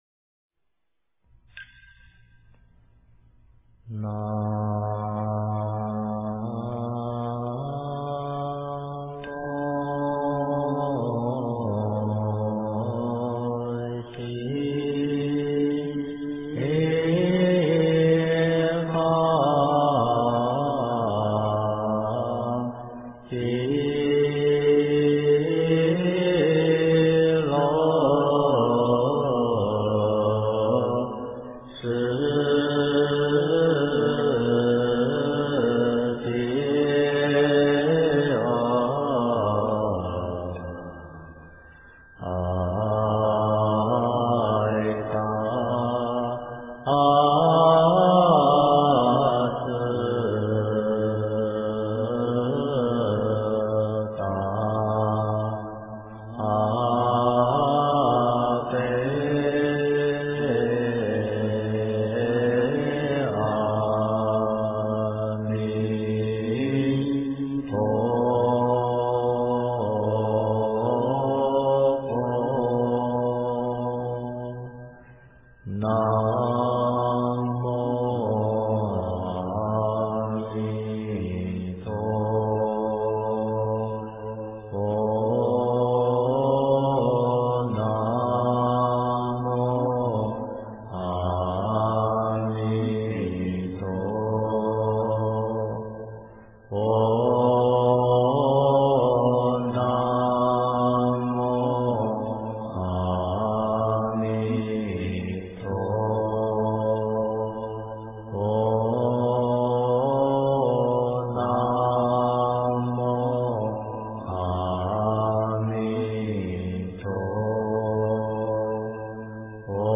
经忏
佛音 经忏 佛教音乐 返回列表 上一篇： 佛说阿弥陀佛经--中国佛学院法师 下一篇： 南无阿弥陀佛--华乐伴奏 相关文章 药师赞--佛光山中国佛教研究院 药师赞--佛光山中国佛教研究院...